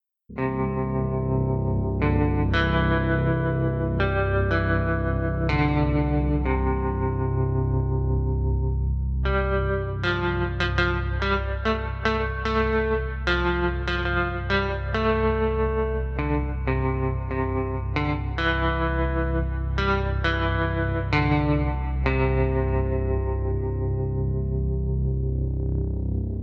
Zwei Amps (wahrscheinlich british und american) sowie ein Federhall bilden die Grundlagen der Klangbildung.
desolate-guitars-2.mp3